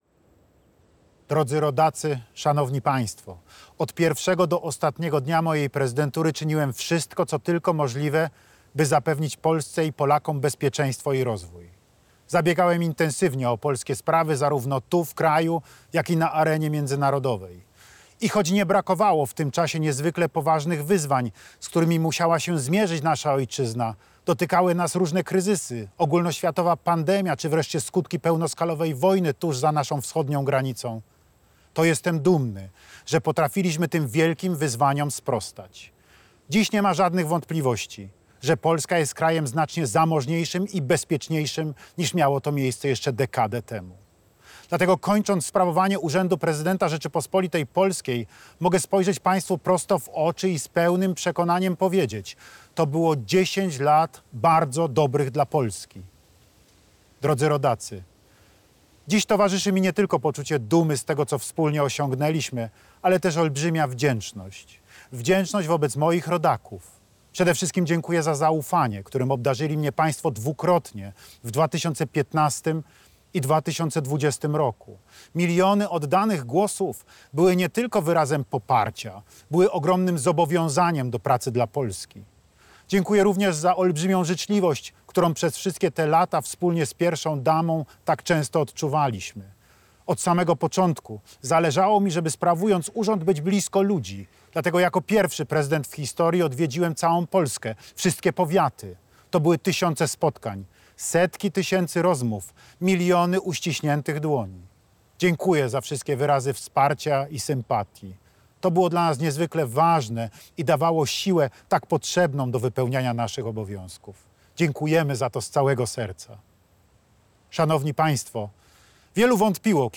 O godzinie 20.00 prezydent Andrzej Duda po raz ostatni wygłosił orędzie jako prezydent Polski.